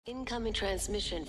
incoming-transmision.mp3